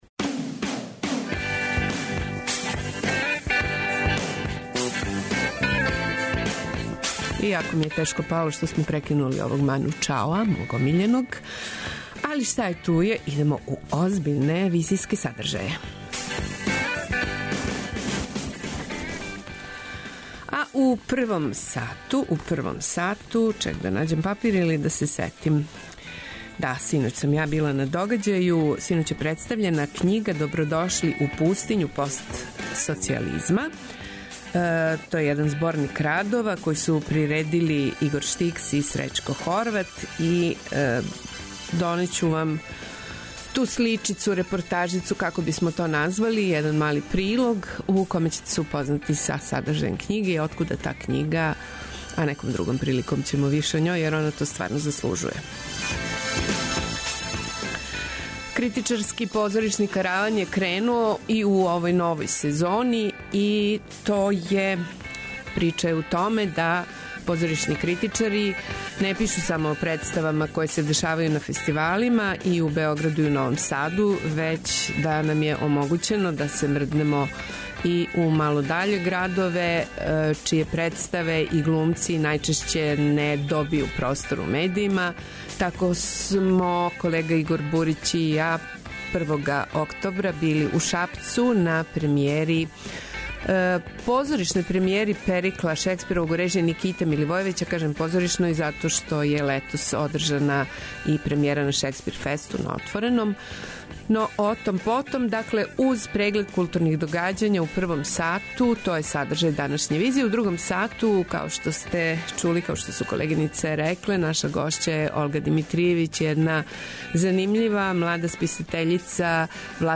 Београд 202 Социо-културолошки магазин, који прати савремене друштвене феномене.